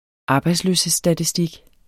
Udtale [ ˈɑːbɑjdsˌløːsheðs- ]